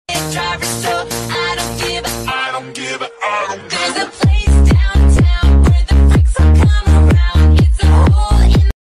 SFX音效